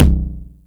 909  Rich Tom.wav